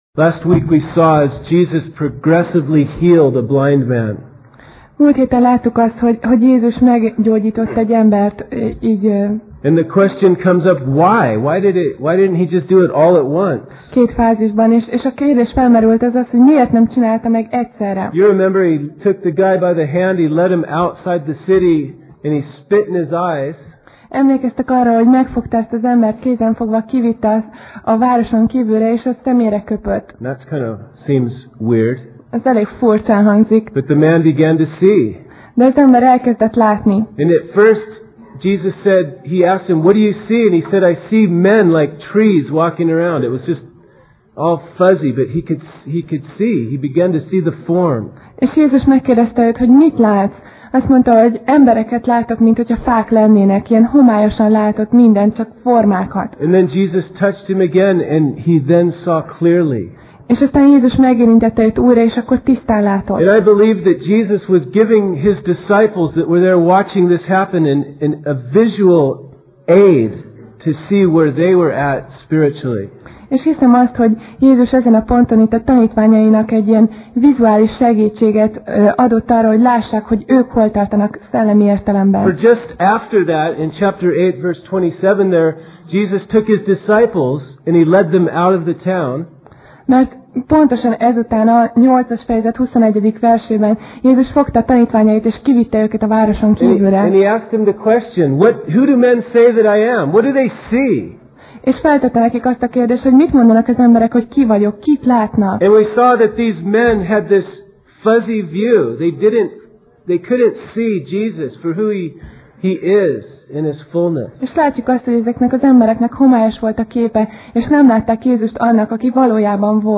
Alkalom: Vasárnap Reggel